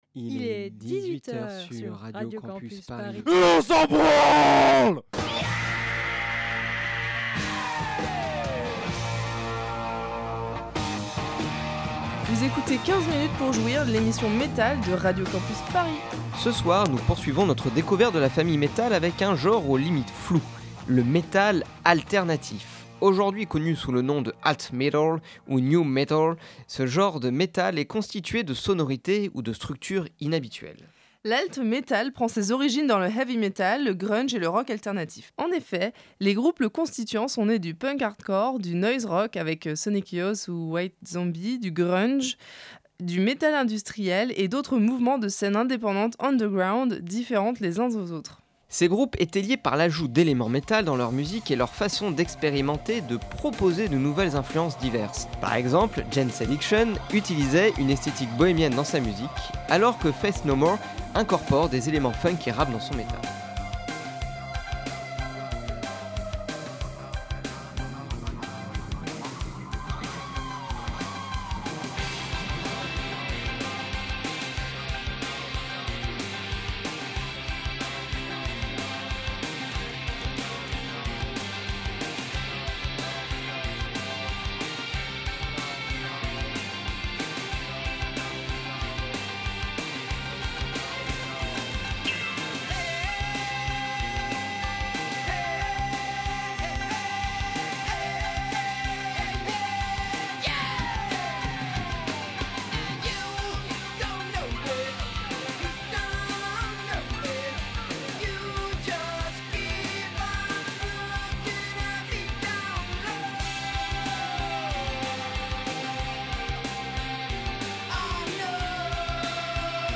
Alternatif et Néo metal
Le metal alternatif et/ou confondu avec le néo ou nü metal, est un peu le bébé de la famille. Rebelle aux formes préétablies, très énervé et radical, il remet tout en question et fait sa montée de sève à coup de riffs barrés et de compositions directes.